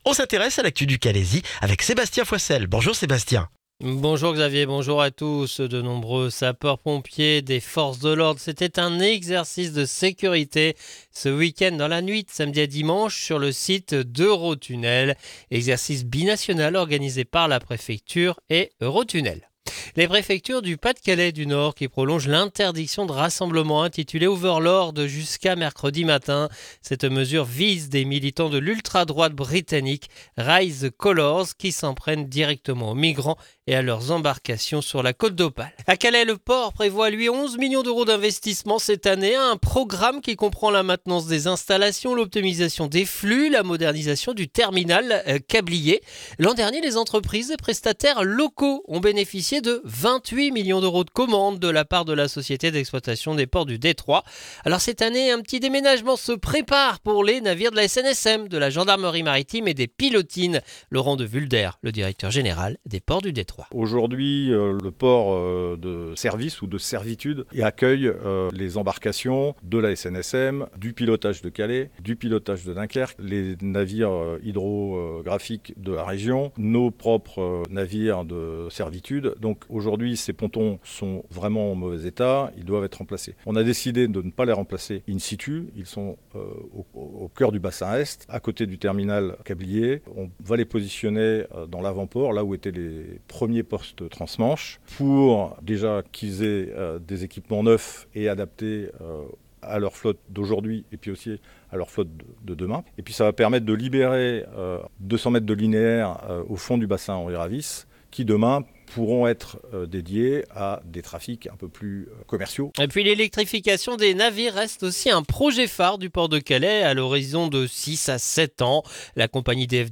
Le journal du lundi 26 janvier dans le Calaisis